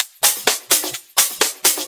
Index of /VEE/VEE2 Loops 128BPM
VEE2 Electro Loop 057.wav